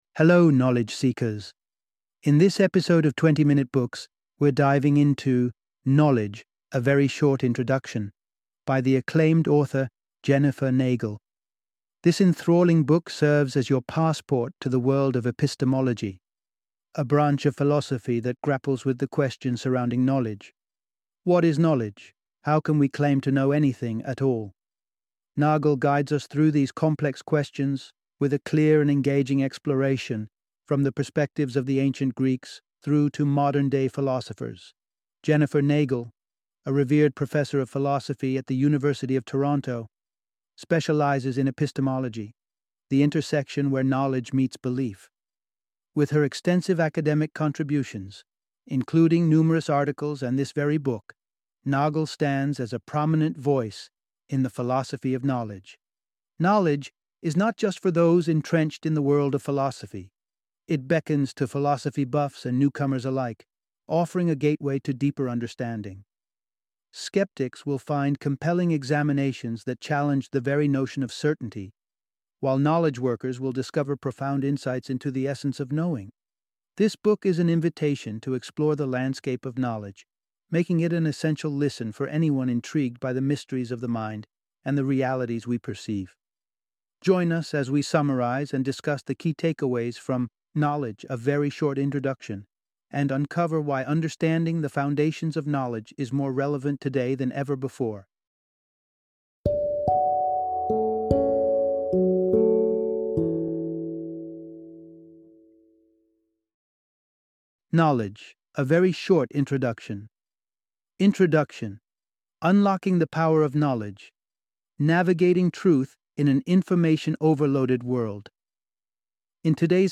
Knowledge - Audiobook Summary